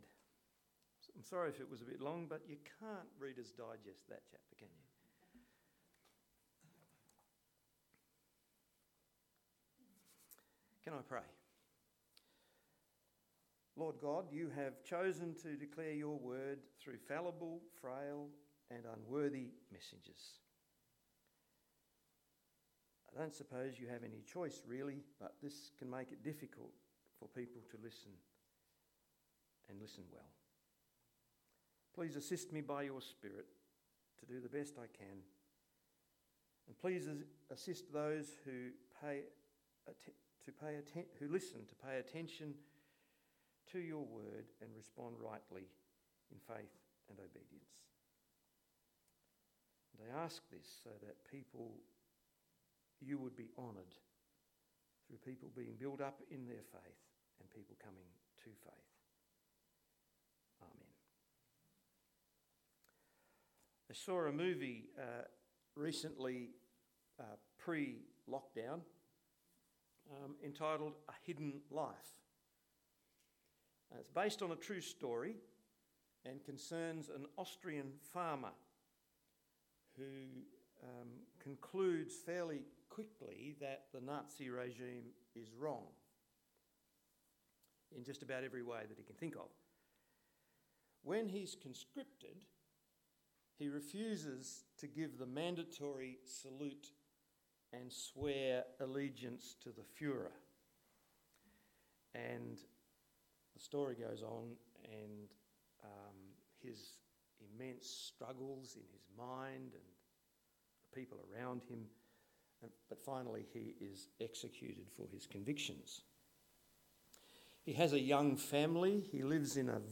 Daniel 3 Service Type: Sunday AM Bible Text